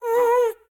Minecraft Version Minecraft Version 25w18a Latest Release | Latest Snapshot 25w18a / assets / minecraft / sounds / mob / happy_ghast / ambient6.ogg Compare With Compare With Latest Release | Latest Snapshot